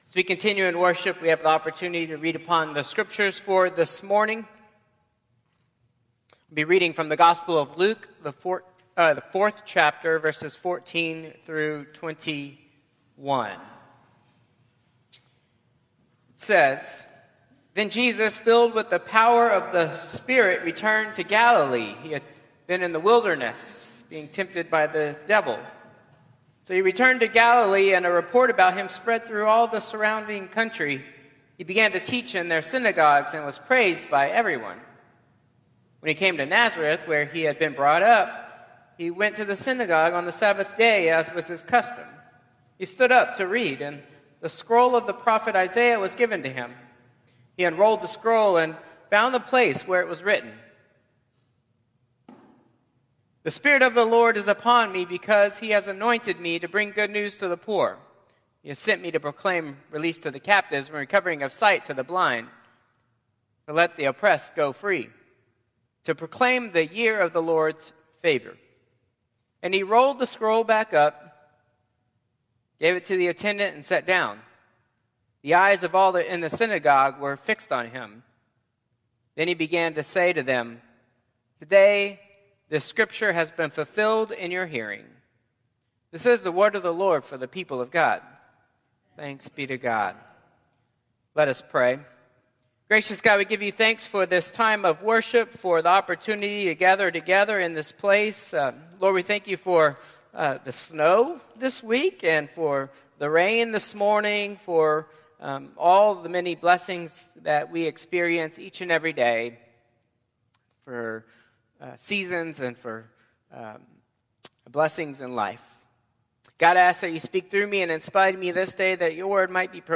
Traditional Service 1/26/2025